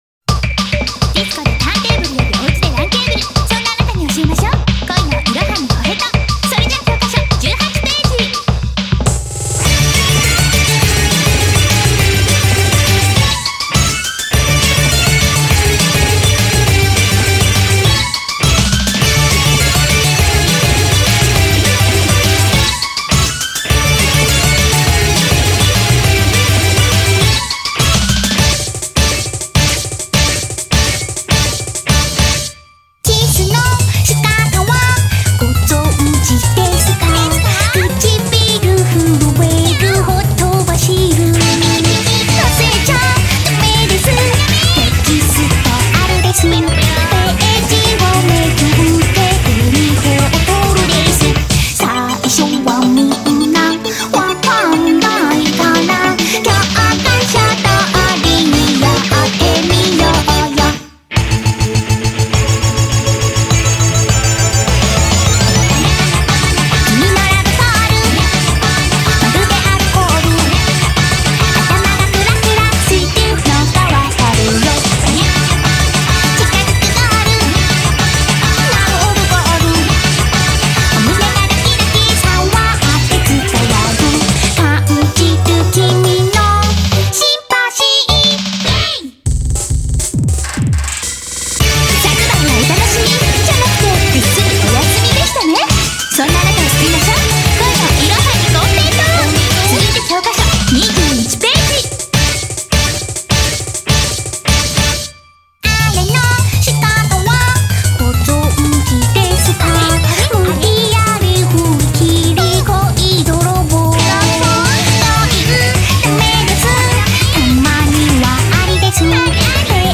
BPM102-205